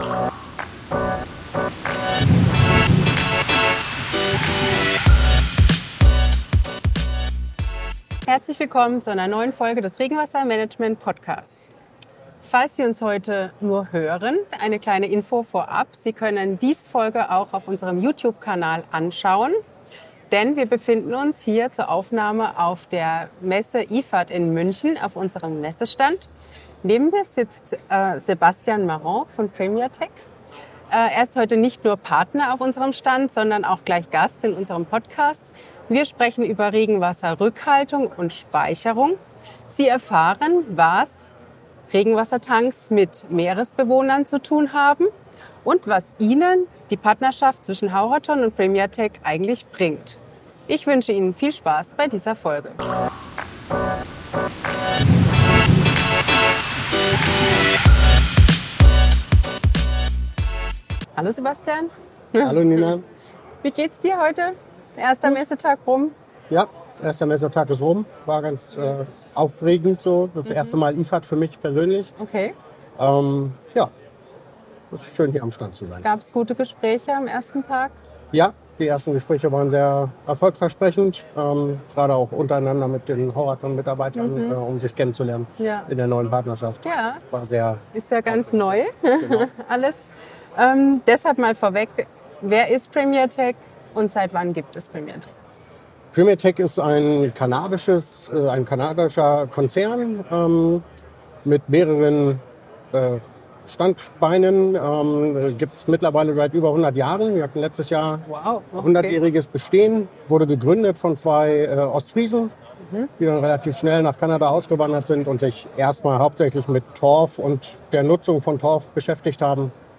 Das Gespräch wurde auf der IFAT in München aufgezeichnet und ist